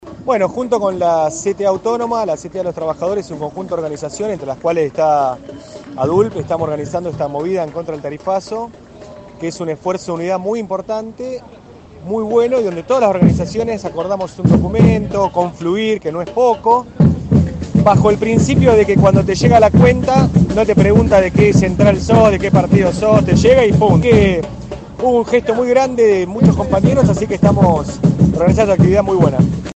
Festival contra el tarifazo en Plaza San Martín